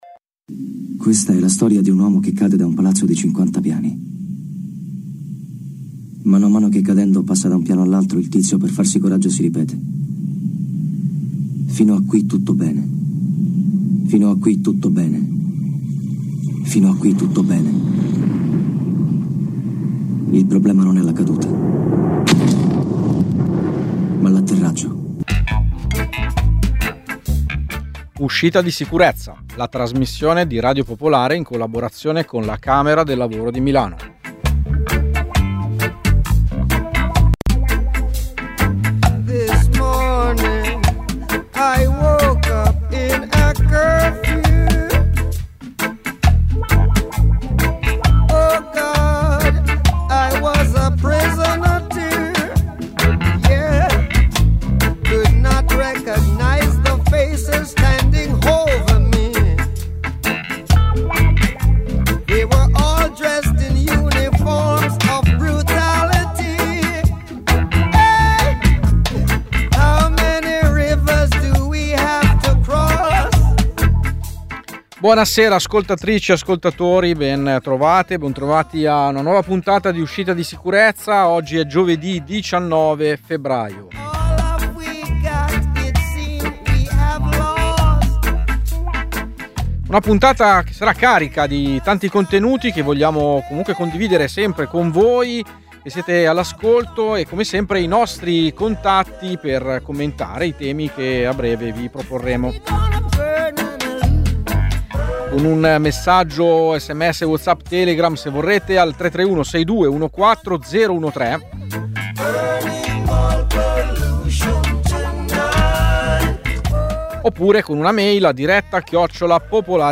Lavoratori del villaggio olimpico di Bormio licenziati prima del termine del contratto, la testimonianza diretta di una hostess addetta al...